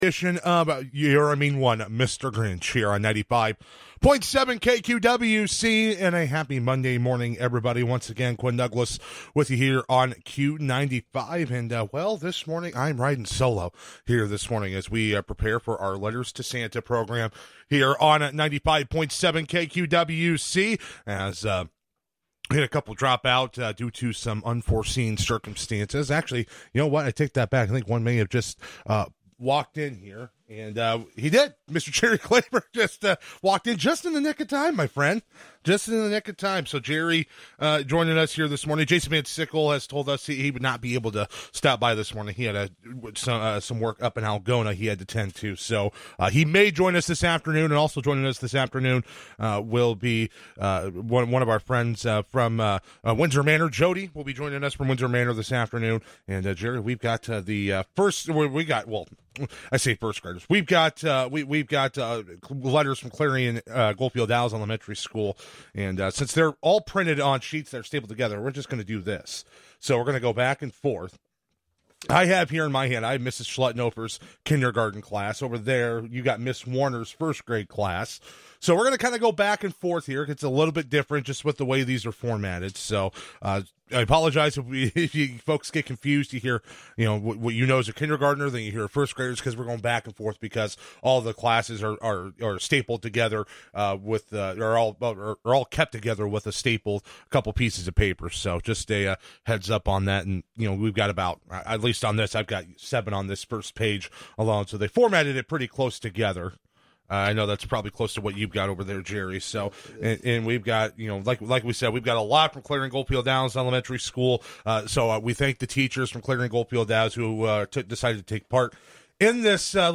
Letters to Santa program, featuring letters from kids at Clarion-Goldfield-Dows Elementary School.